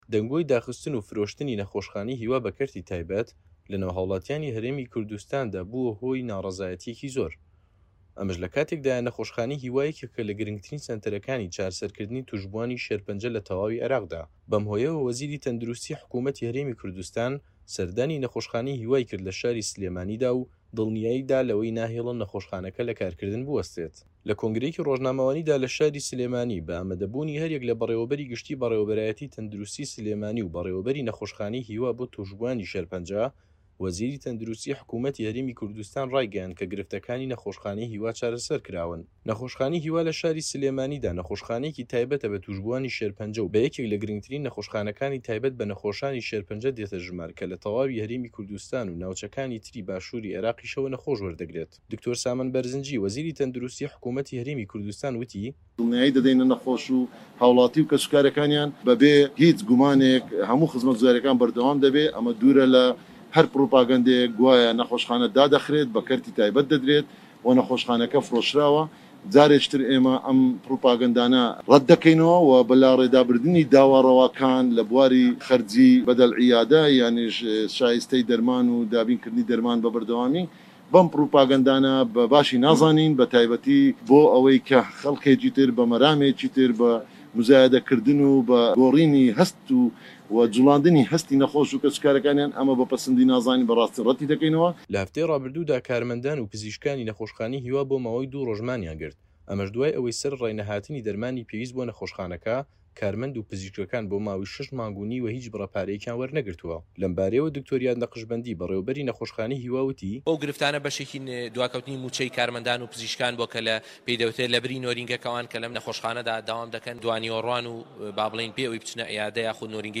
ڕاپۆرتی